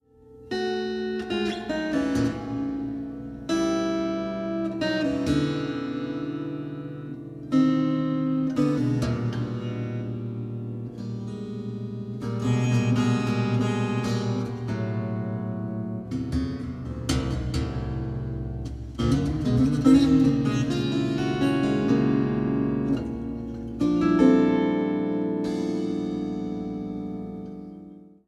36-string Double Contraguitar, 30-string Contra-Alto guitar